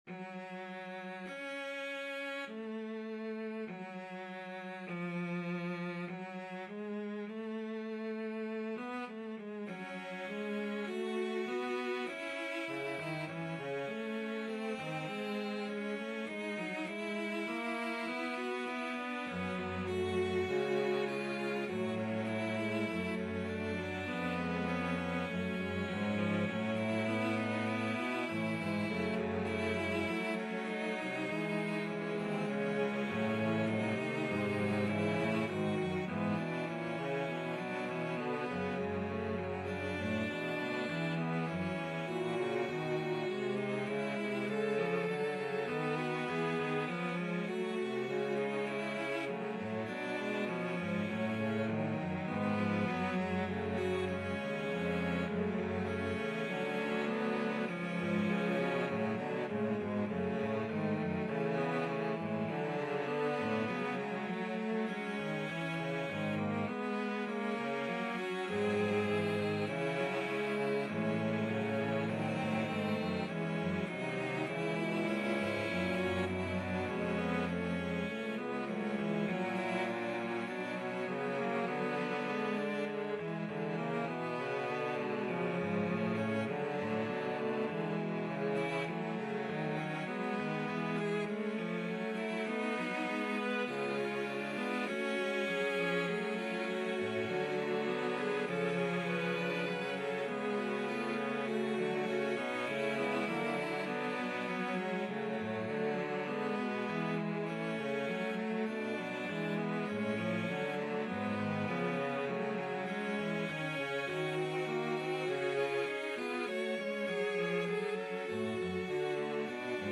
2/2 (View more 2/2 Music)
Cello Quartet  (View more Advanced Cello Quartet Music)
Classical (View more Classical Cello Quartet Music)